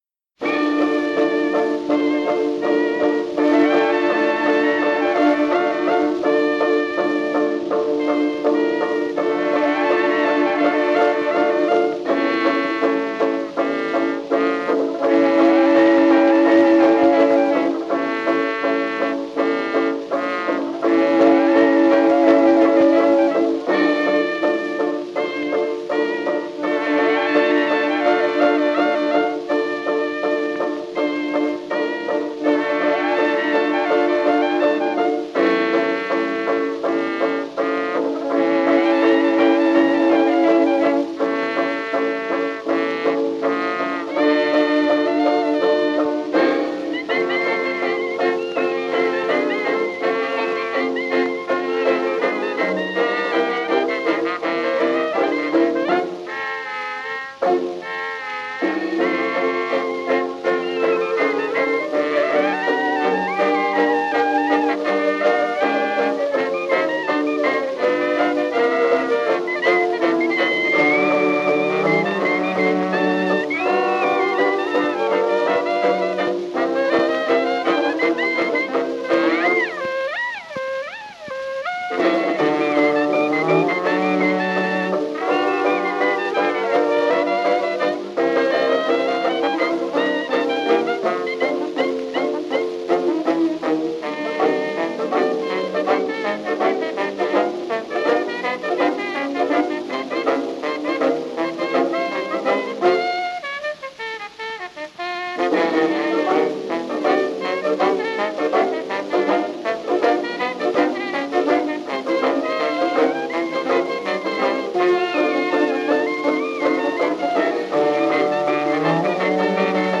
dance band
trumpet
tenor saxophone
banjo
tuba